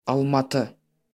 ^ /ælˈmɑːti/[6] or /ˈælməti/;[7] Kazakh: Алматы / Almaty, IPA: [ɑɫmɑtʰə́]
Kk-almaty.ogg.mp3